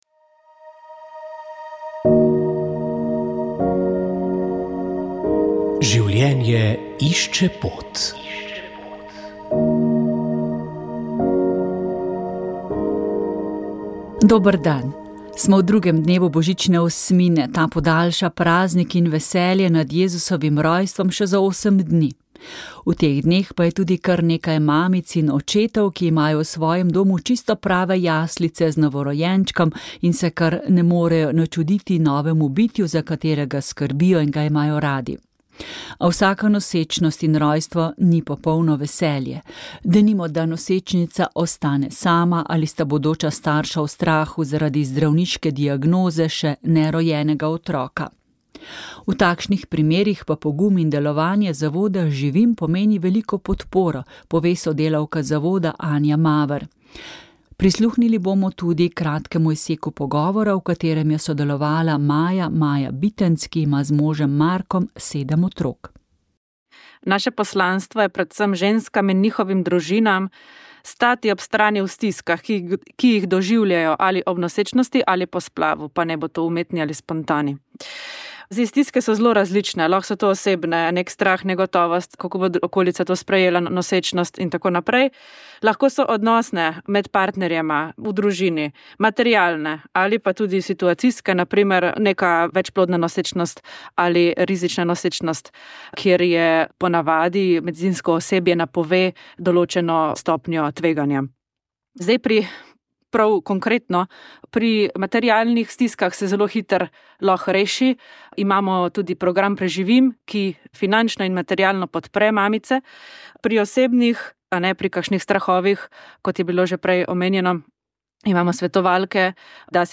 Sv. maša iz cerkve Marijinega oznanjenja na Tromostovju v Ljubljani 23. 12.